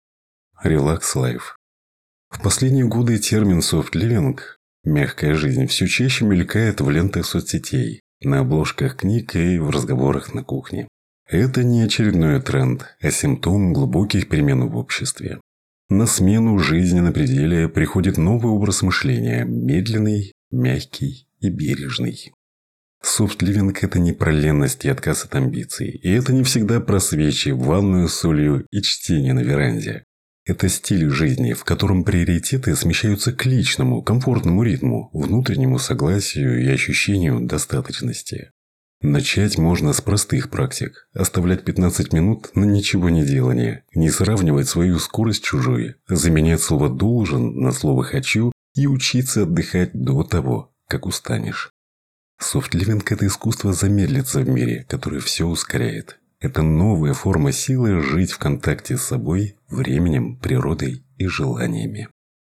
Муж, Закадровый текст/Средний